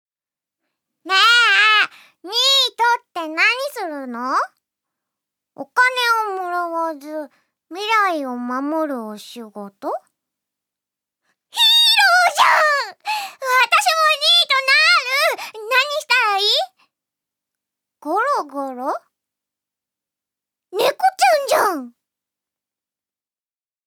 セリフ８